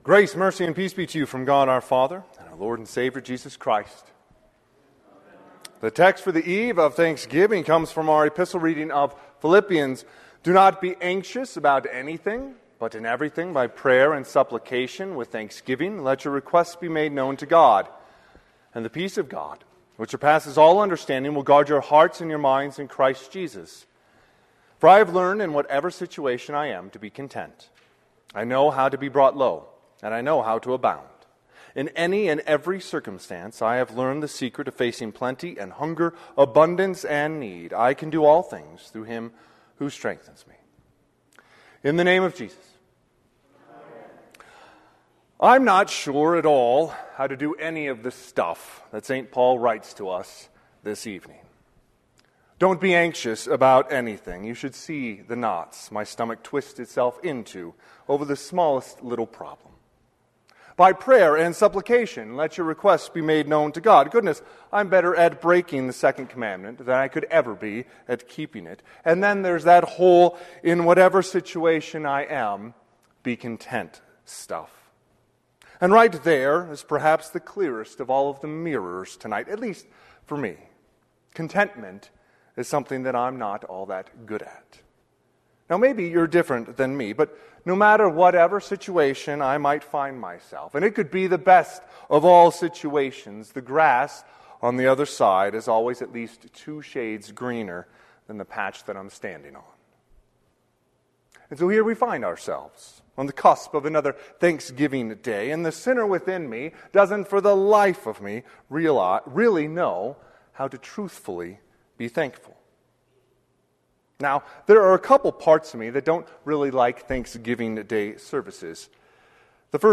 Sermon - 11/27/2024 - Wheat Ridge Lutheran Church, Wheat Ridge, Colorado